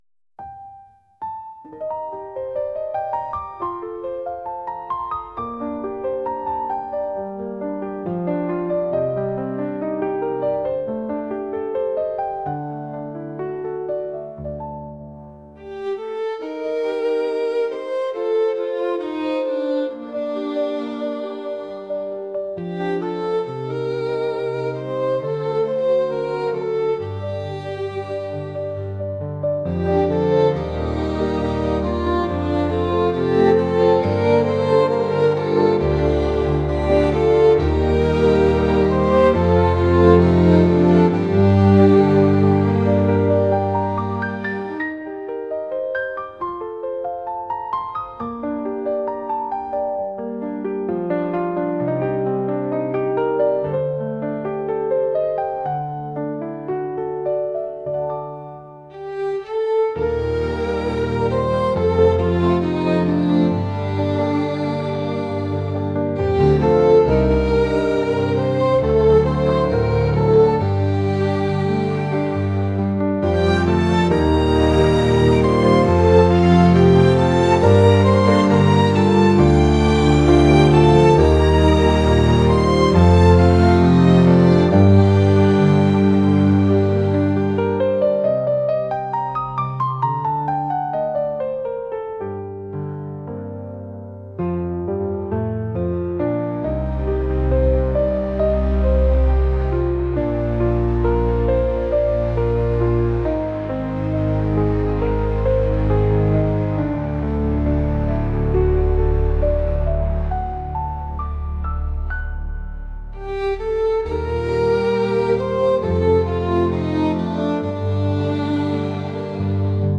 cinematic | classical | ambient